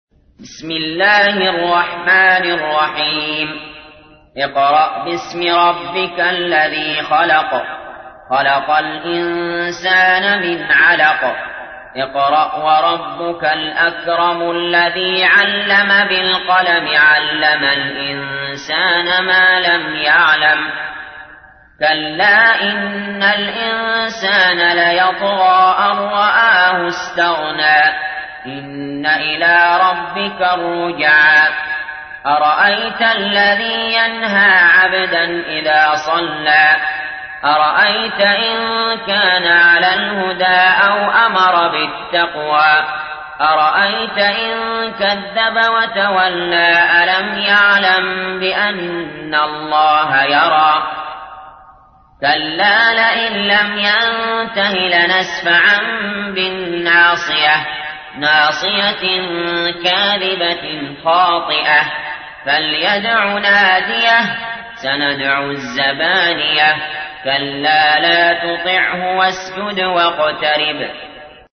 تحميل : 96. سورة العلق / القارئ علي جابر / القرآن الكريم / موقع يا حسين